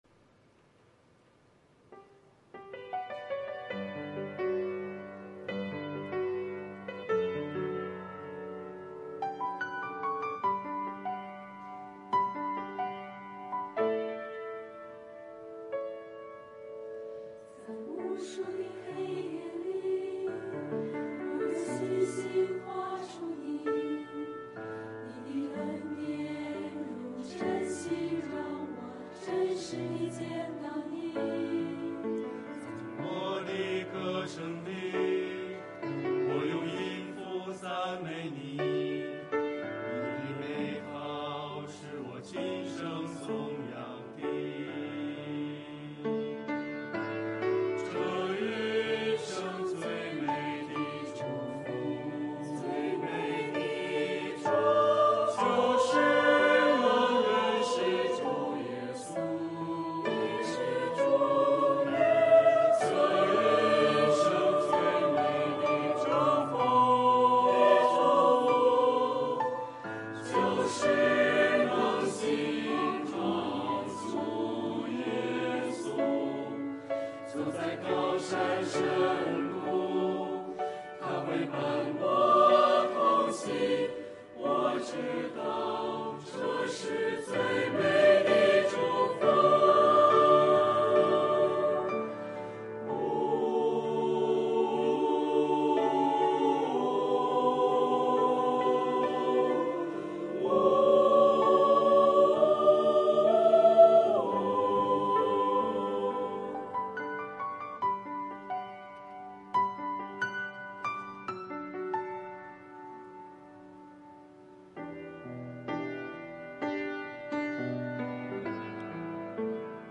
诗班献诗